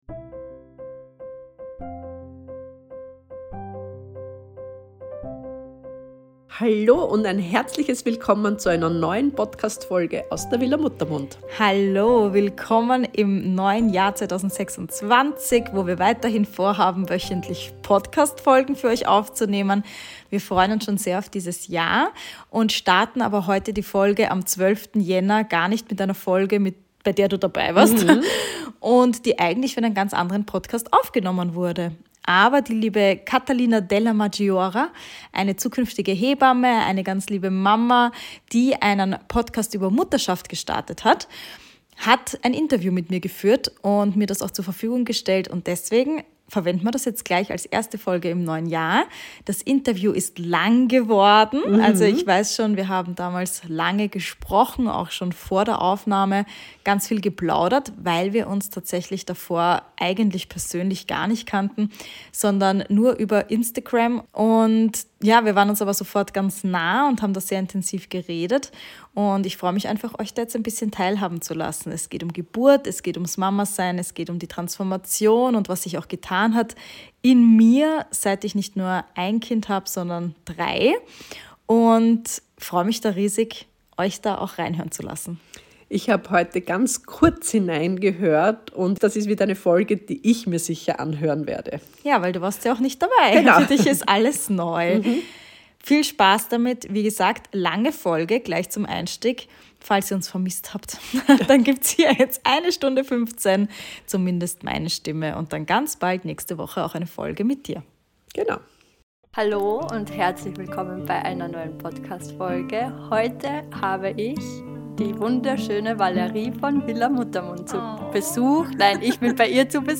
In dieser besonderen Crossover-Folge tauschen sich die beiden Podcasterinnen